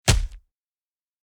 Download Punching sound effect for free.
Punching